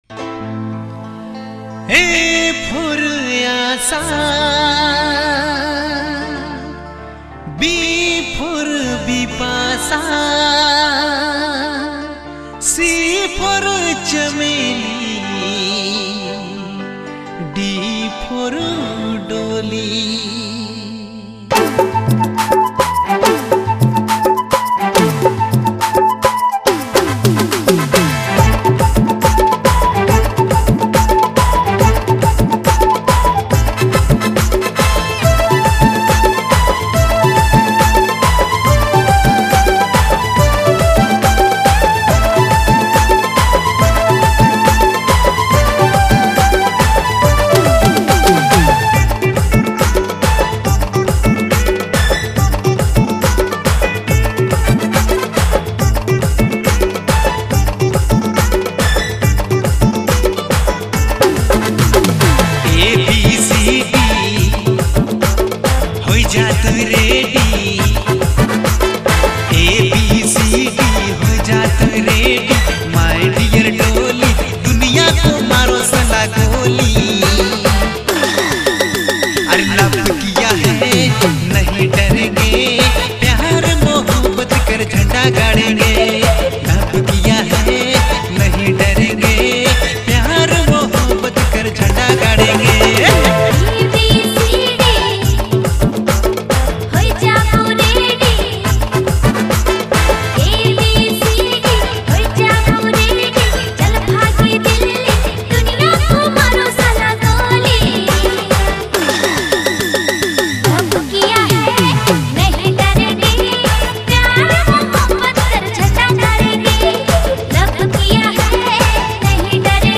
is a lively Nagpuri DJ remix
Nagpuri dance song